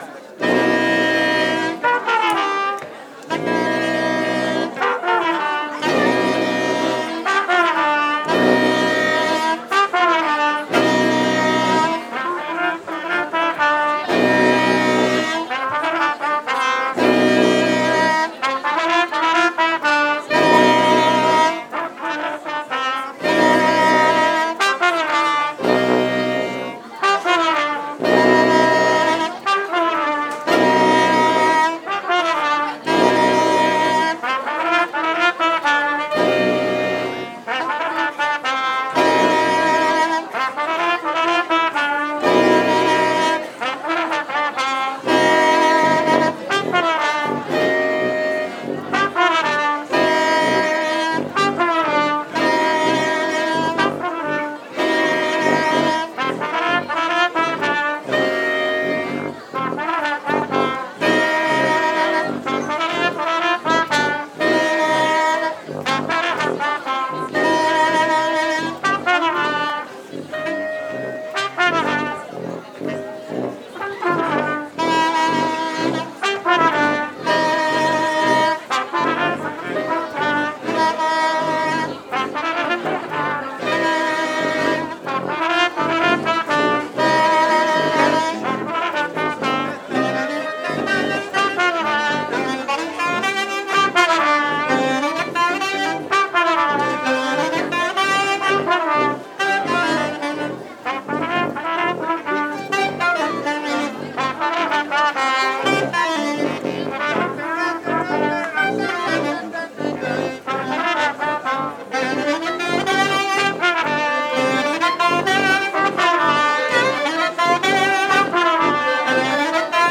05_pile_menu-orchestre.mp3